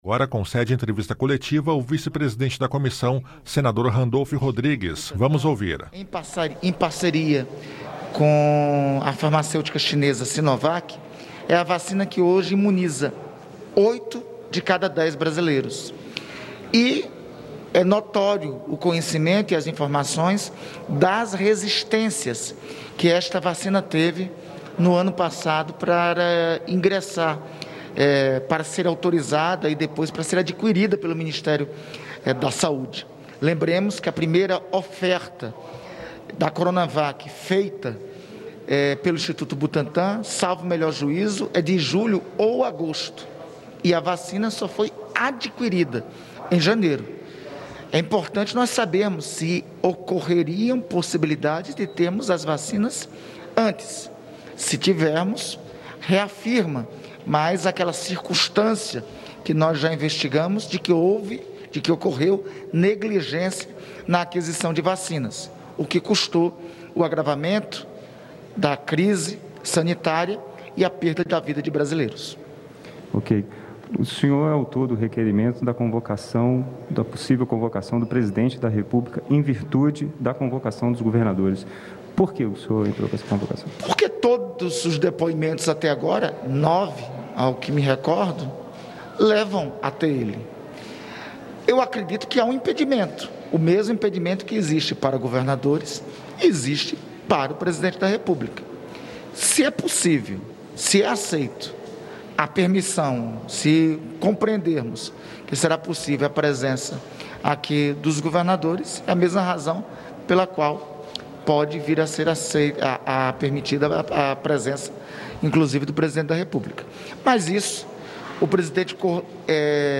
Entrevista coletiva com o vice-presidente da CPI da Pandemia, Randolfe Rodrigues
O vice-presidente da CPI da Pandemia, senador Randolfe Rodrigues (Rede-AP), concede entrevista coletiva e fala sobre as expectativas para o depoimento do diretor do Instituto Butantan, Dimas Covas, marcado para esta quinta-feira (27). O senador também fala sobre requerimento de sua autoria para convocar o presidente da República, Jair Bolsonaro, para prestar esclarecimentos na CPI.